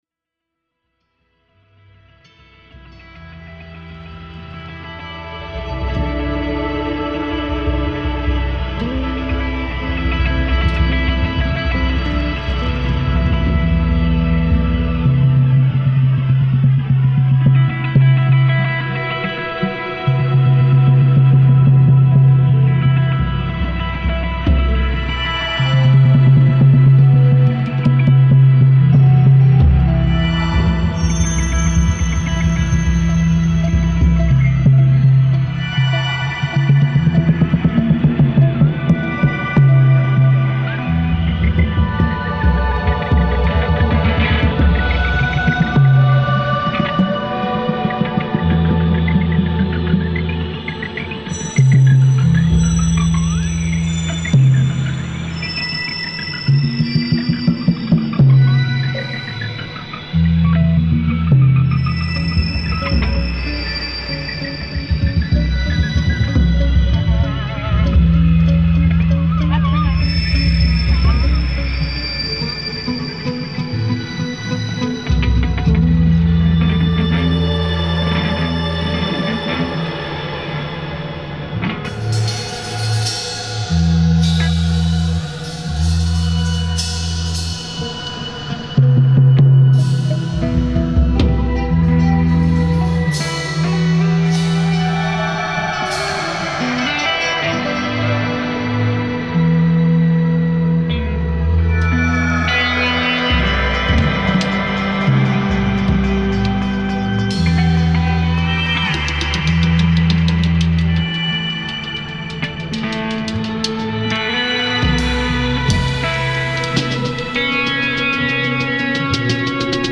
Beschreibung vor 1 Tag Am 10. Oktober 2025 traf sich Cookin’X – Das Kochende Etwas zu einer Session in Werl. Dies ist das zweite Stück der Session.
Synthesizer
E-Gitarre, Flügelhorn
Bass, Samples
Schlagzeug Mehr